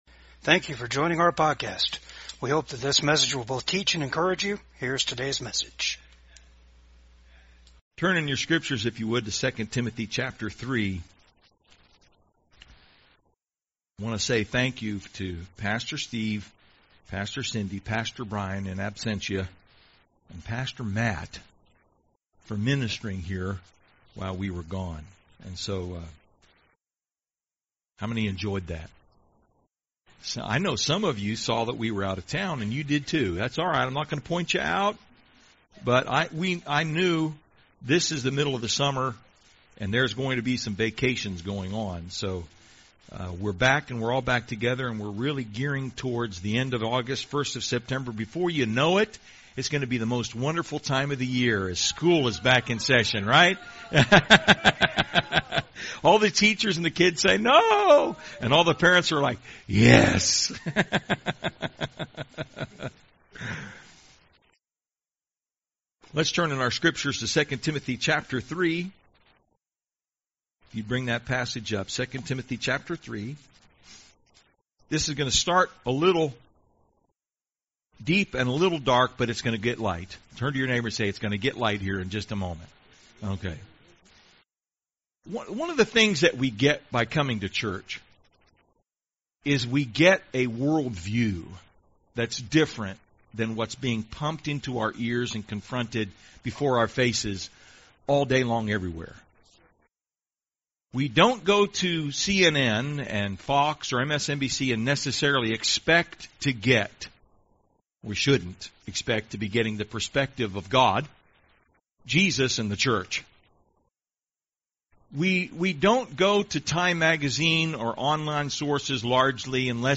2 Timothy 3:1-5 Service Type: VCAG SUNDAY SERVICE GIVE GLORY AND THANKS TO GOD.